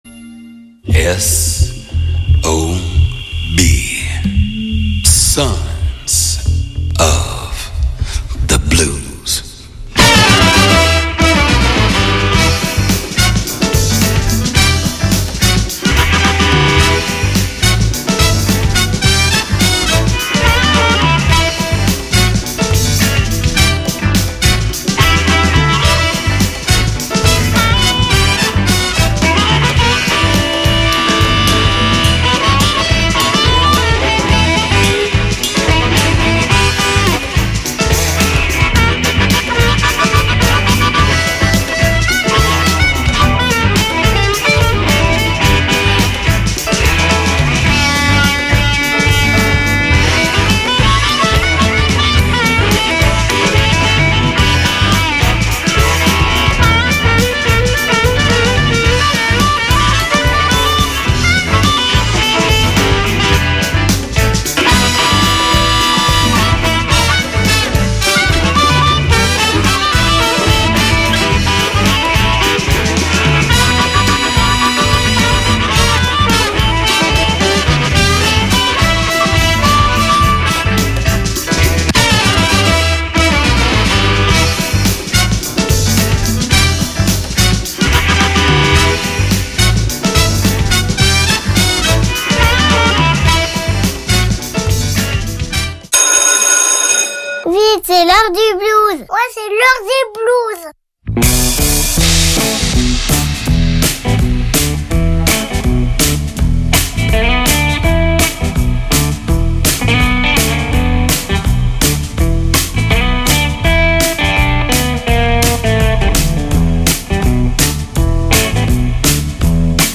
Sons of Blues : musiques blues
Puisque nous sommes tous des fils et filles du blues, il est bon de se retrouver chaque jeudi à 21H pour 1H de blues d’hier, d’aujourd’hui ou de demain.I